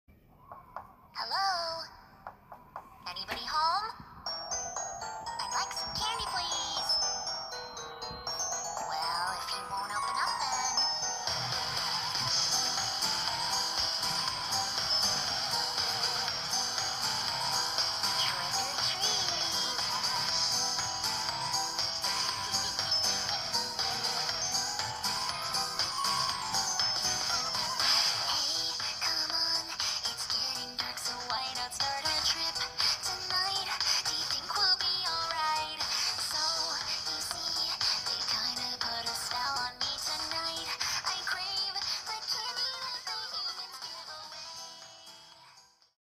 English cover